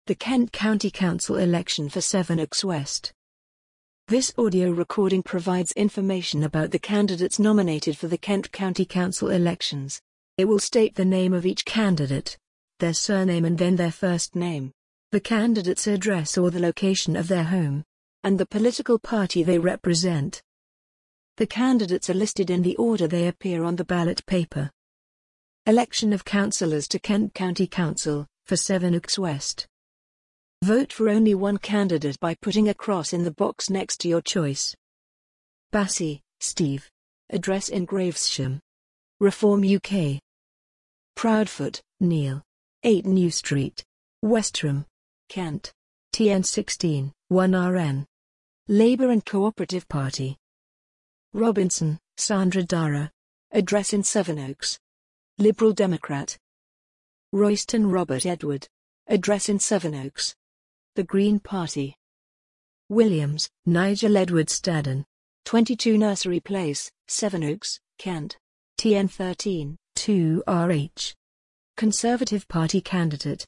Sevenoaks District Council download - KCC election audio ballot papers | Your council | Elections and voting